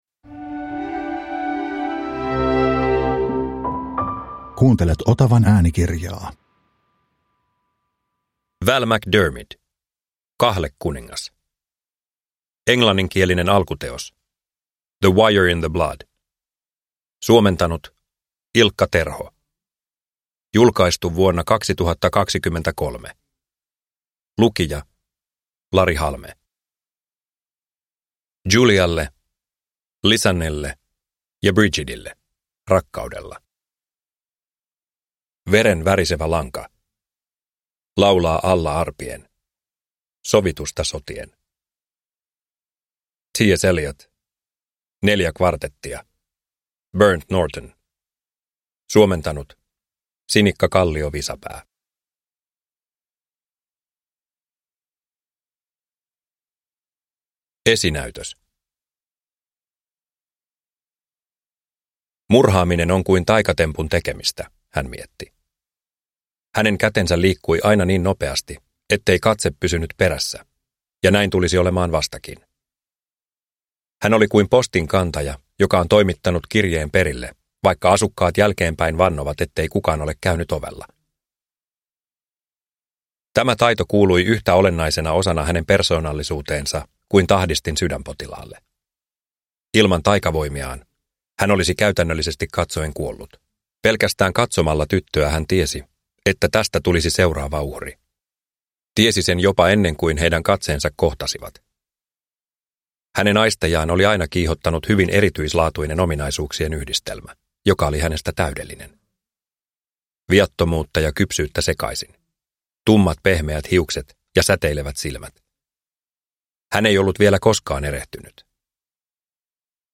Kahlekuningas – Ljudbok – Laddas ner